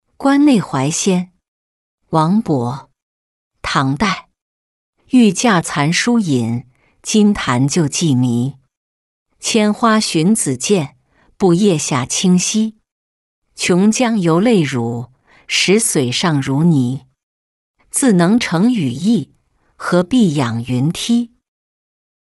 观内怀仙-音频朗读